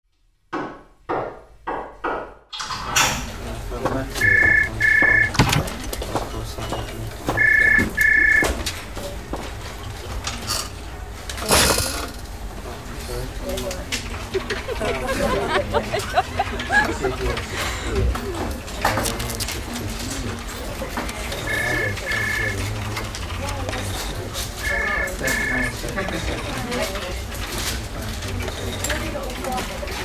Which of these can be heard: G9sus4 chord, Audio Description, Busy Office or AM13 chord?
Busy Office